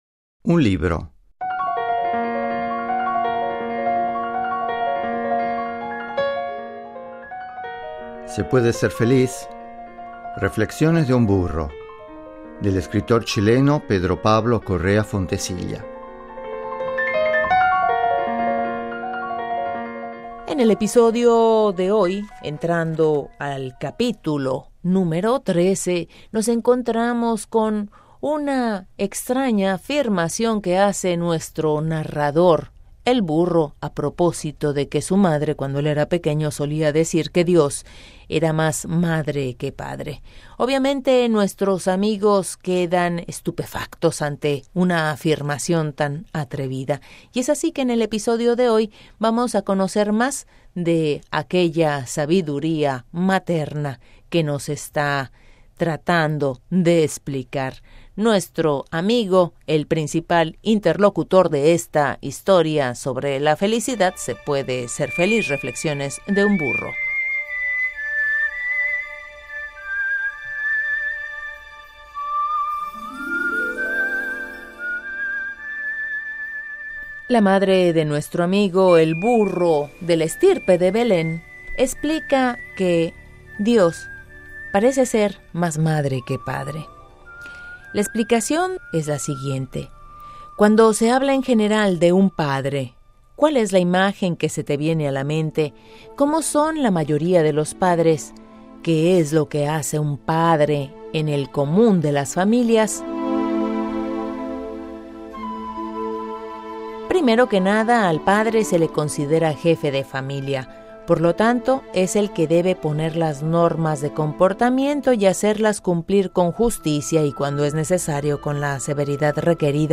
Adaptación Radiofónica de un libro: